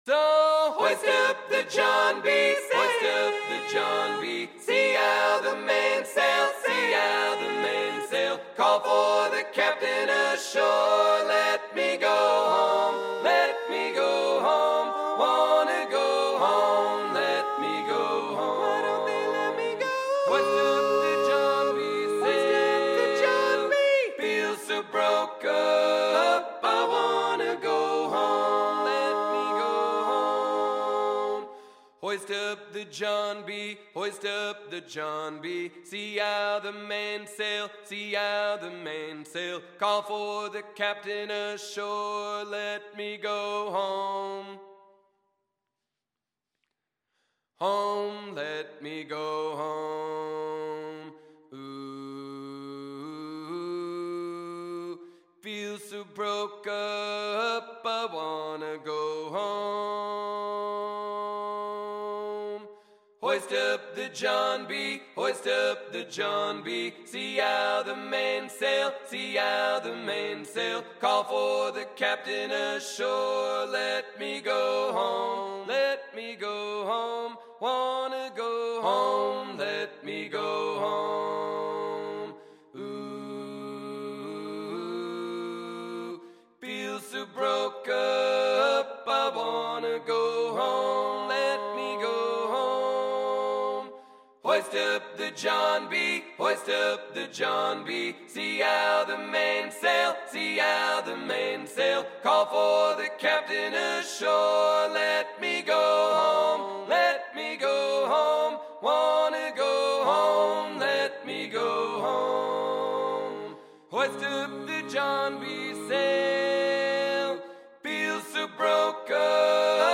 Performed live in my studio